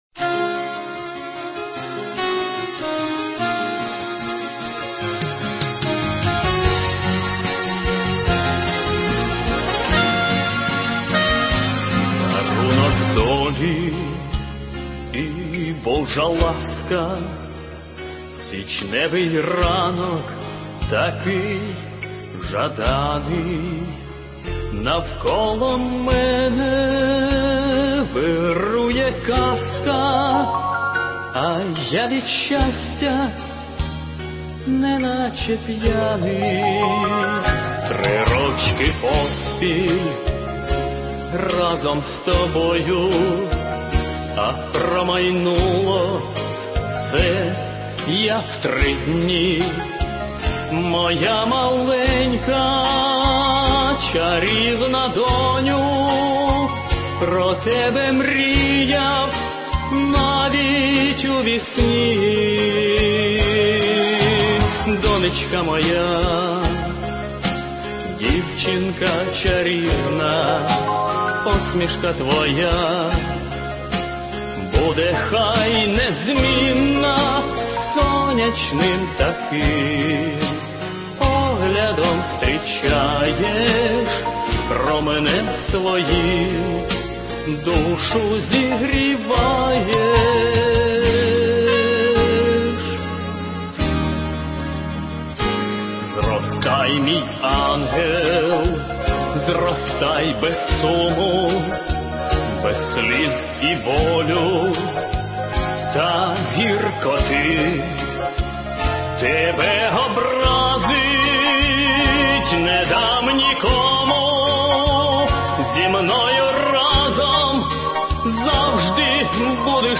Песню «Донечка моя» исполняет автор её украинского текста — Алексей Мозговой